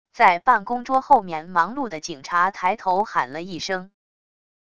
在办公桌后面忙碌的警察抬头喊了一声wav音频